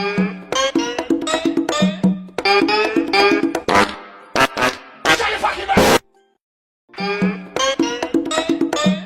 Memes
Sybau Fart